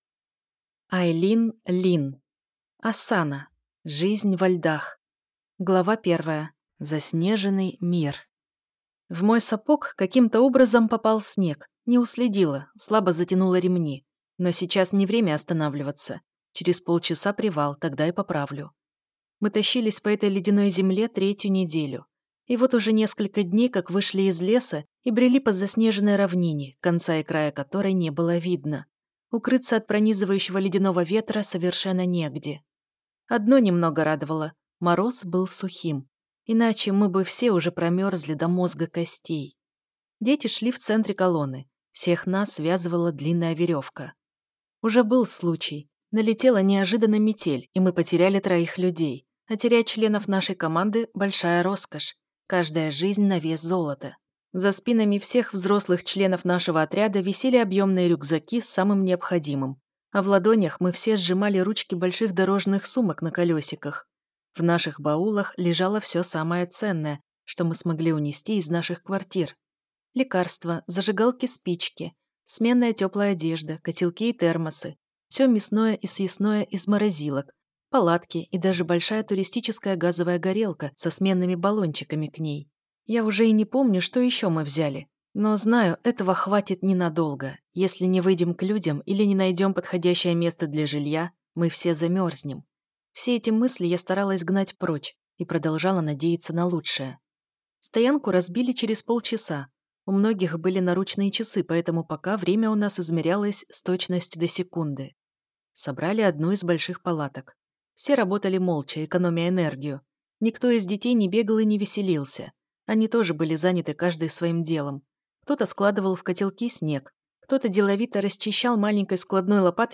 Аудиокнига «Проект «Валькирия». Серия 6». Автор - Ерофей Трофимов.